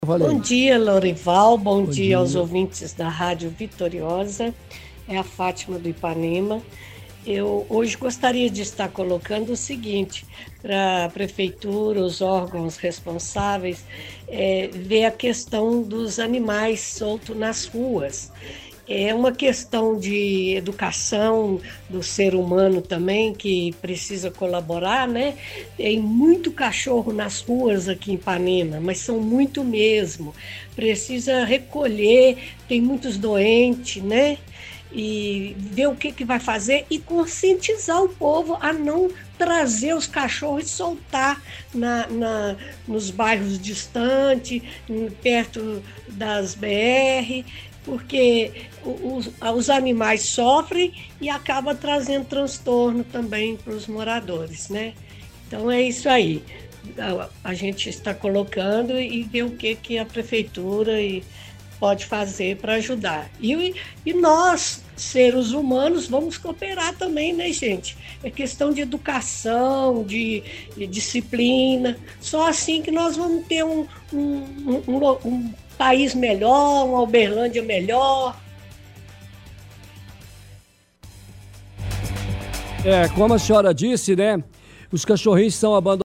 – Ouvinte do bairro Ipanema fala sobre a questão dos animais soltos nas ruas. Diz que tem muitos cachorros abandonados no bairro, e também, que é preciso recolher esses animais, pede maior conscientização da população para não abandonarem cachorros nas ruas. Questiona o que a prefeitura pode fazer para ajudar.